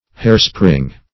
Hairspring \Hair"spring`\ (-spr[i^]ng`), n. (Horology)